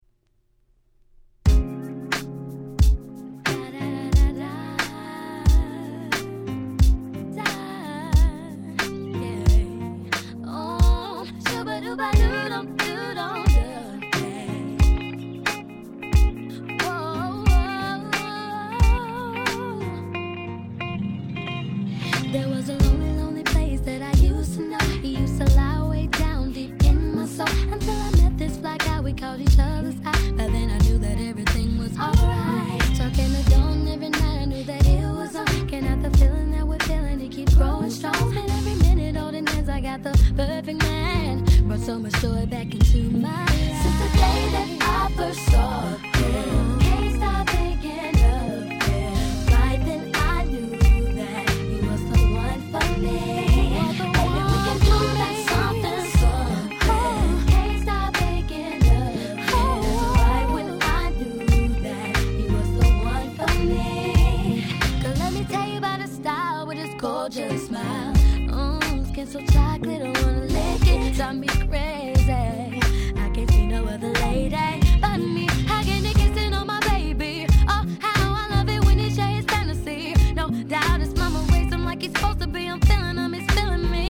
切ないHip Hop Soul